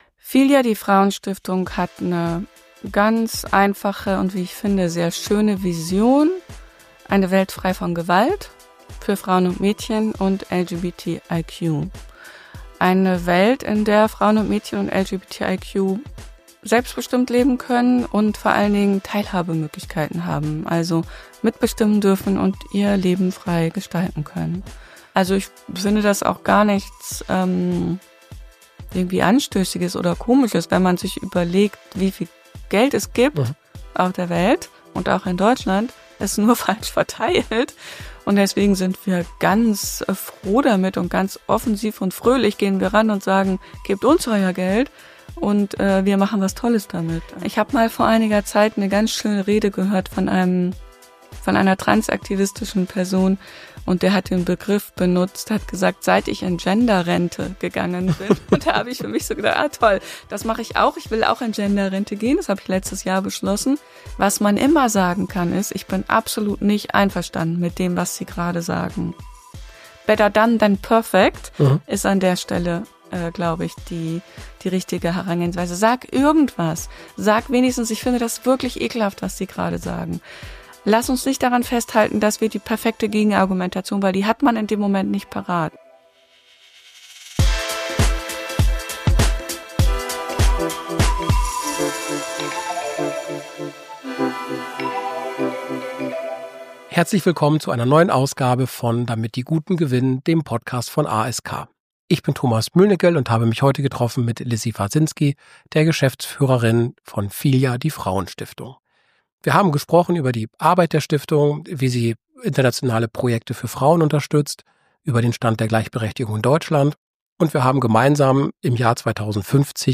Ein Gespräch über Gerechtigkeit, Engagement und die Kraft feministischer Philanthropie.